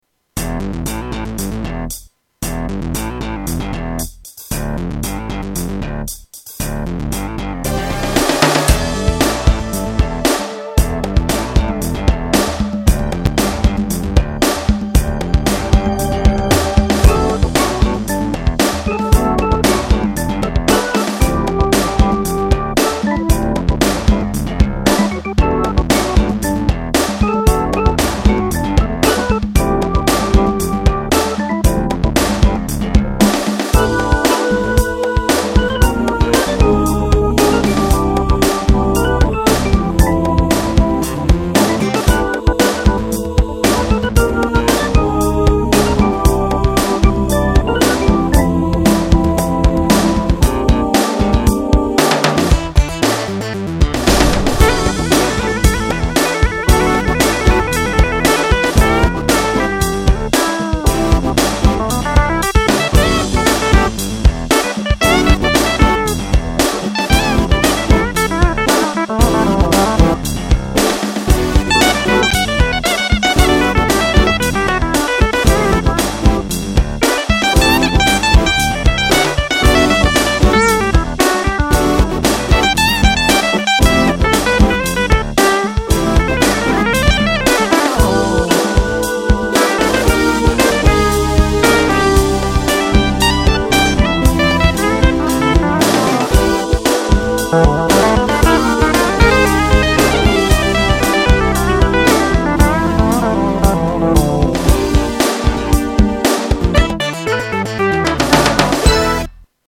Tags: Kurzweil K2500 Kurzweil K2500 clips Kurzweil K2500 sounds Kurzweil Synthesizer